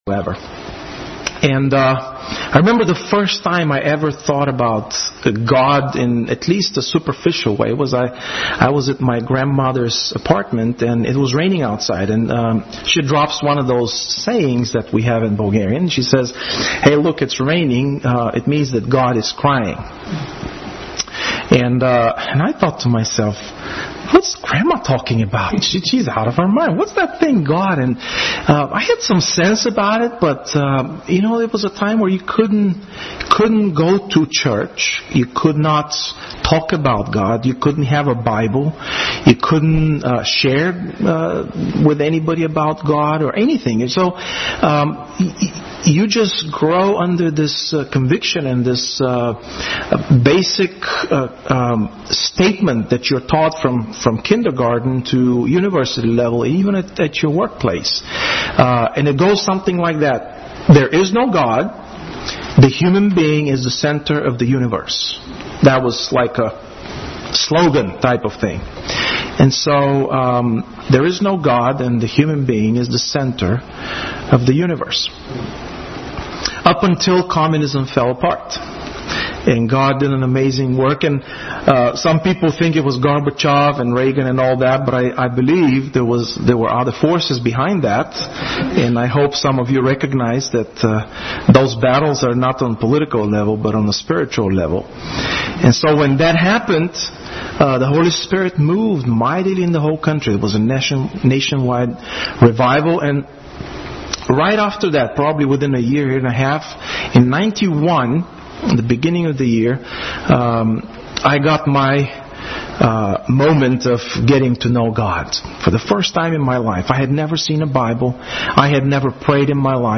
Service Type: Midweek Special Service